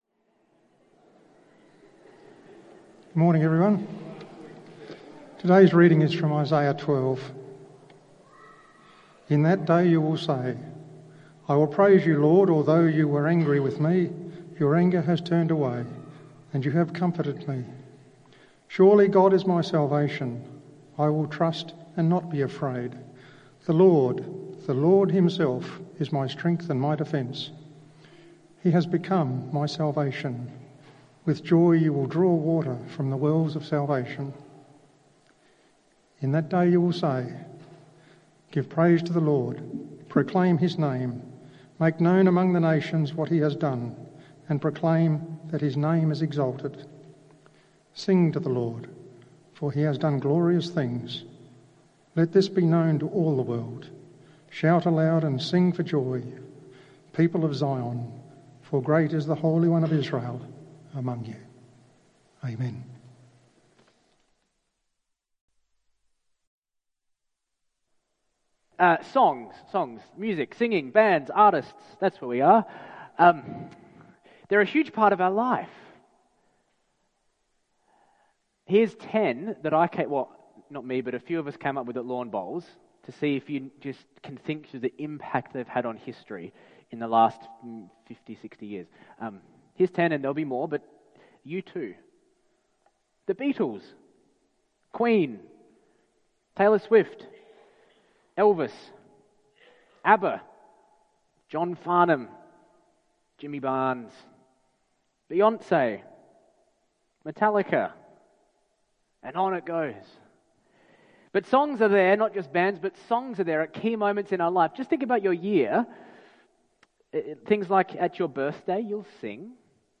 Isaiah 12:1-6 Talk Summary Introduction: Songs in Our Lives Songs play a significant role in shaping culture and marking key life moments.